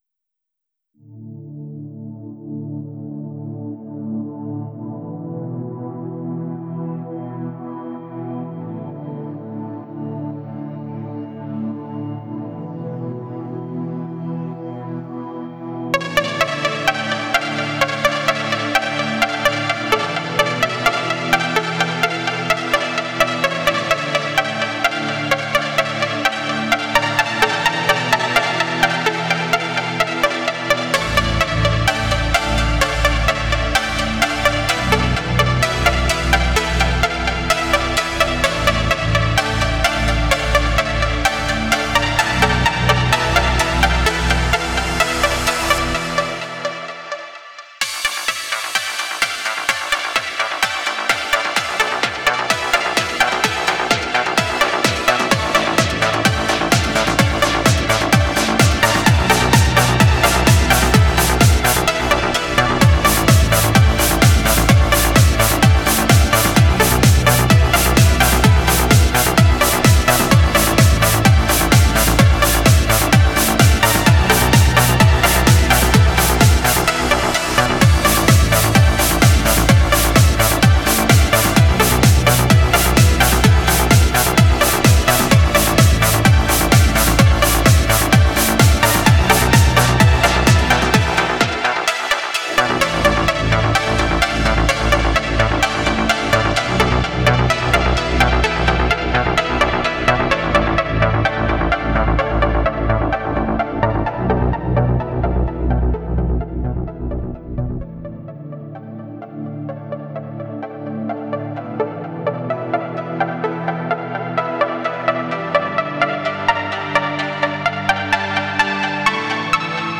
BEST ELECTRO A-F (35)